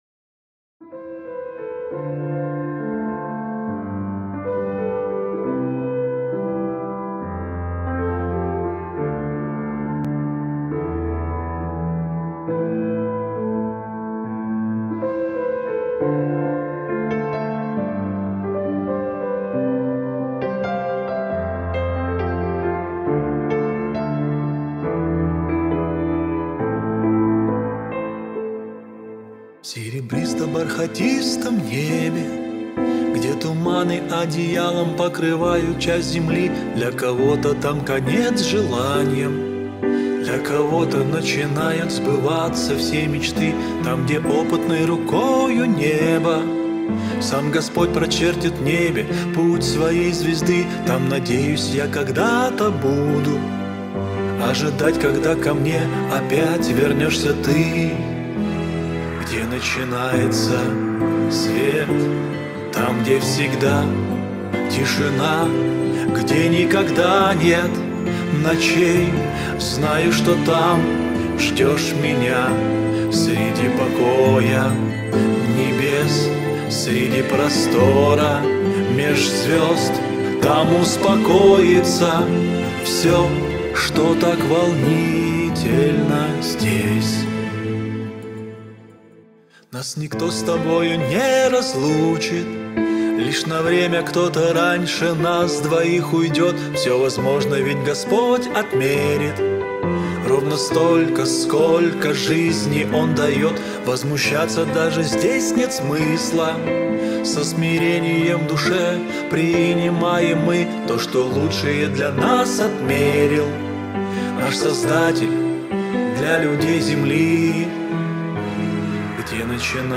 песня
99 просмотров 101 прослушиваний 11 скачиваний BPM: 75